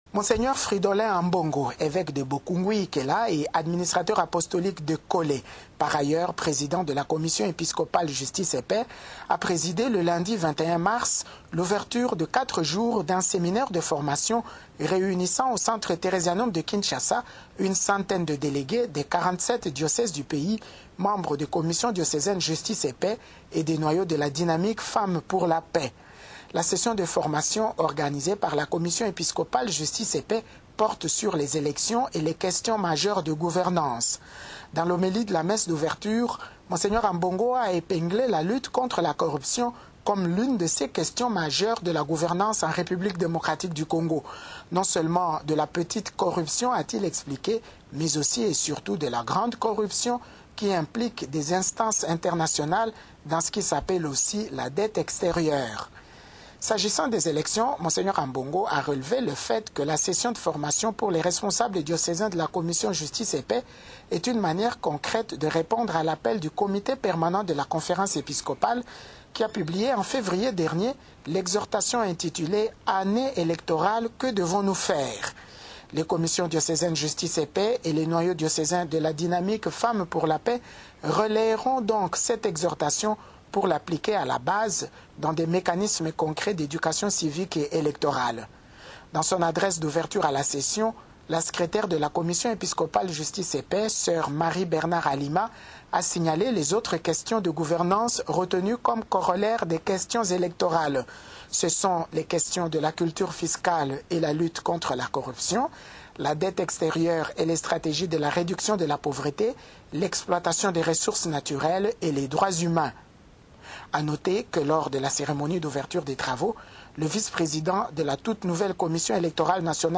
nous en fait un compte-rendu